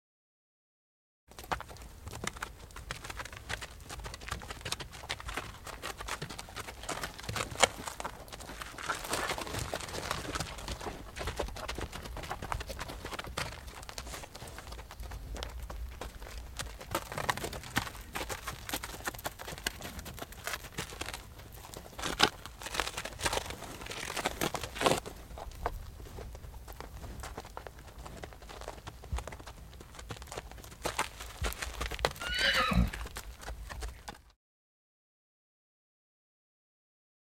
Horses, Two; Milling About On Dirt With Short Whinny At Tail, Medium Perspective.